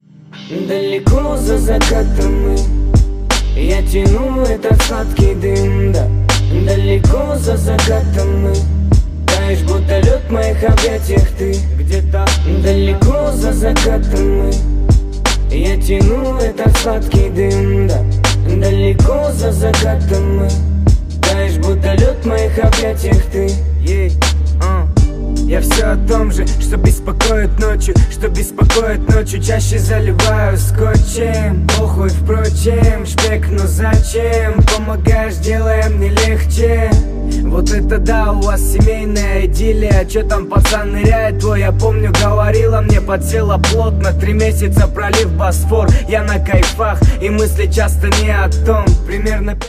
лирика
Хип-хоп
русский рэп
качающие